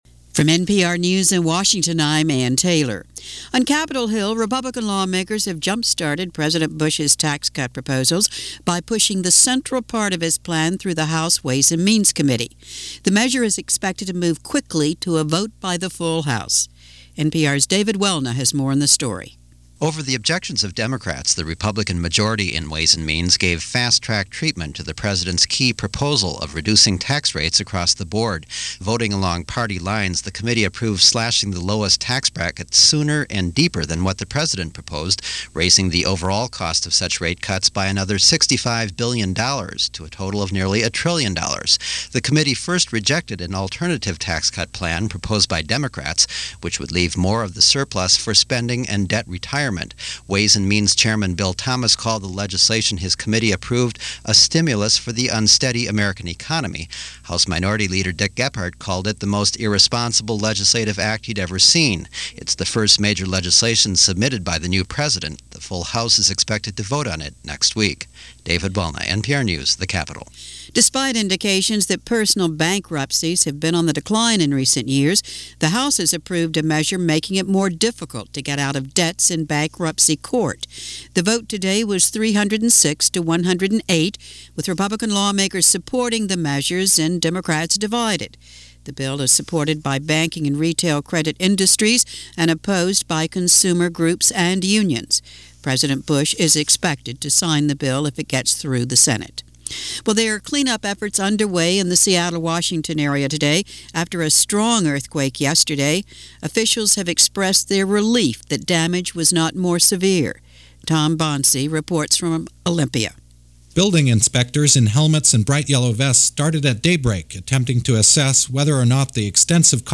And that’s much of what happened on this March 1, 2001 as presented by NPR News.
News-for-March-1-2001.mp3